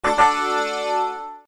tada.mp3